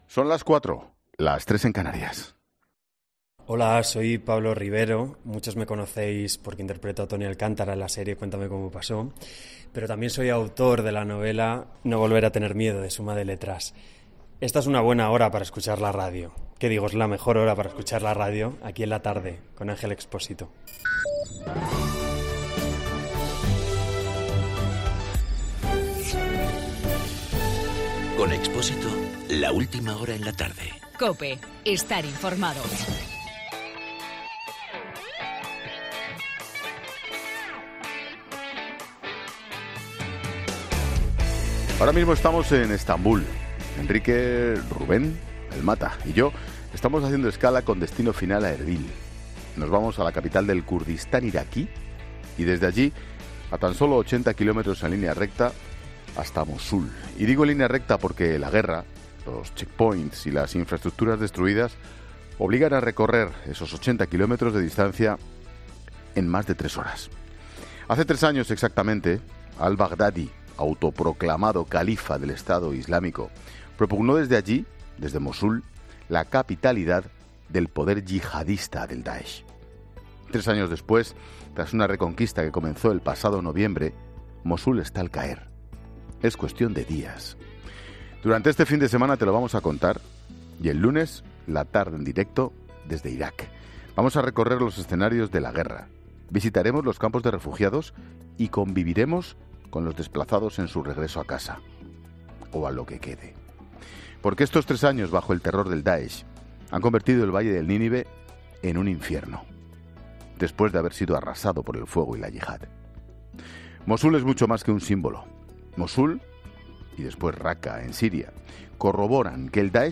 AUDIO: El monólogo de Ángel Expósito a las 16h desde Estambul, de camino a Mosul.